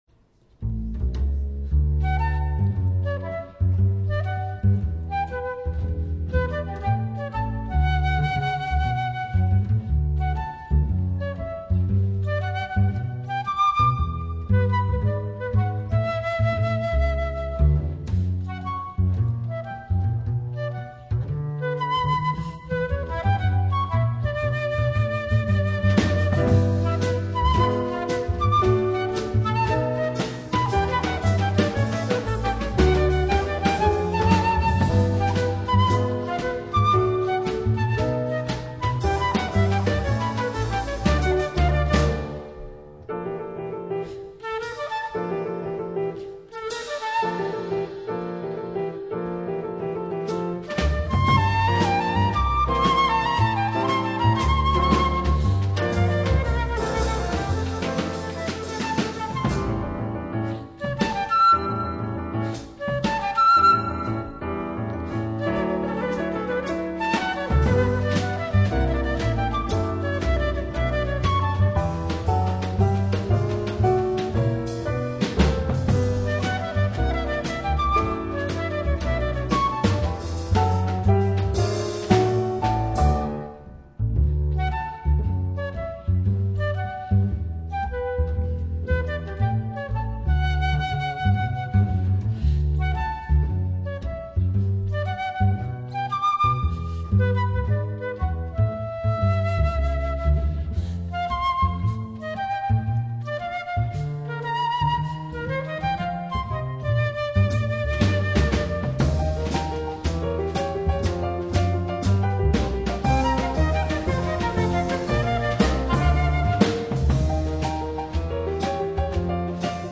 (Live)
flute and jazz trio